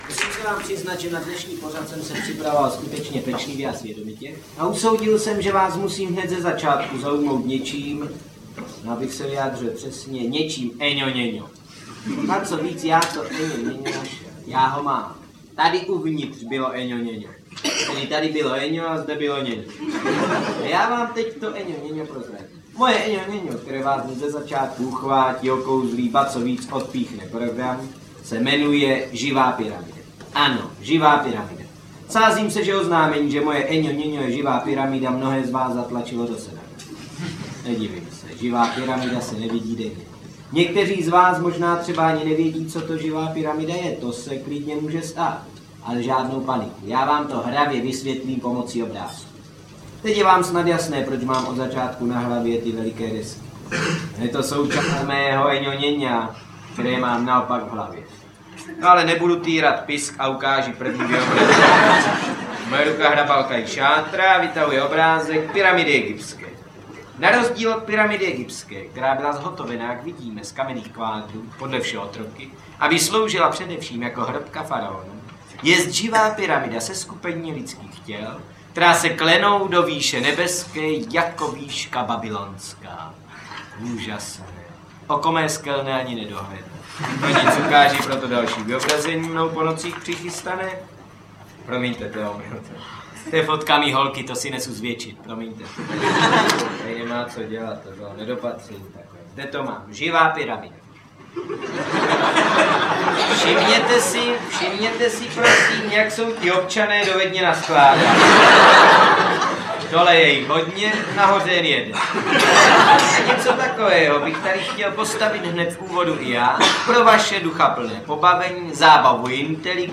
Nejlepší léta komického tria v nahrávkách zcela neznámých, nově objevených i těch, které publikum už léta miluje!
Ukázka z knihy
Nejlepší společná léta tohoto humoristického tria jsou zde zachycena na záznamech jednotlivých představení z archivu divadla Semafor, Supraphonu a České televize.